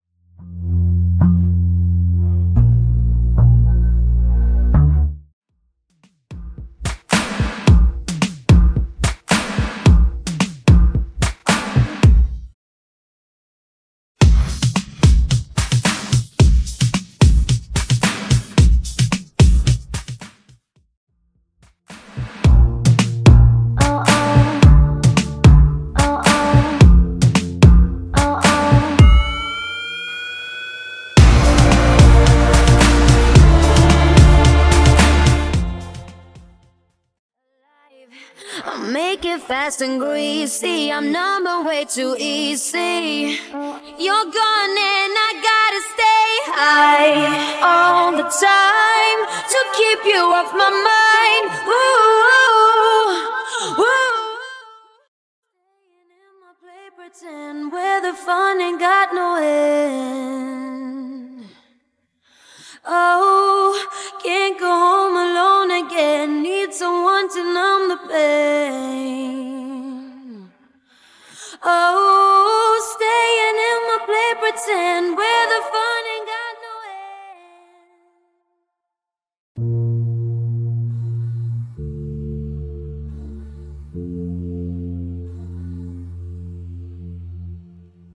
Backing Vocals Stem
Bassline Stem
Percussion & Drums Stem
Synth Waves, Strings and Choir Effects Diy Stem